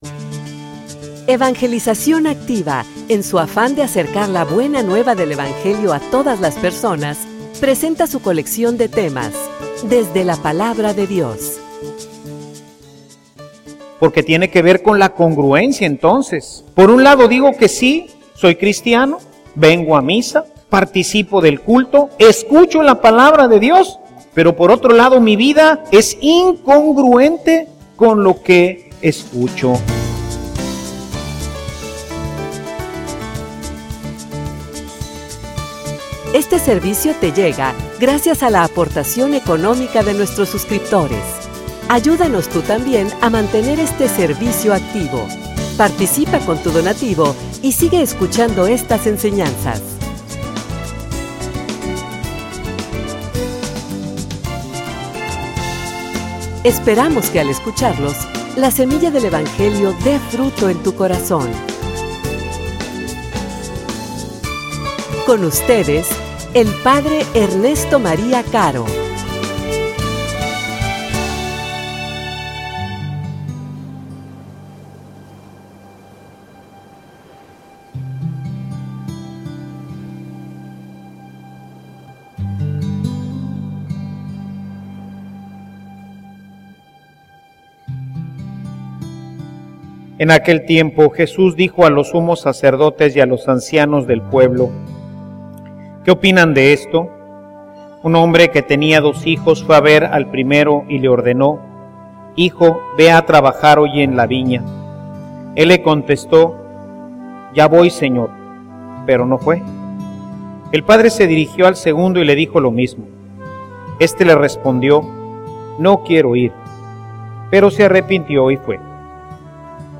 homilia_Ser_congruente.mp3